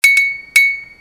Category: iPhone Ringtones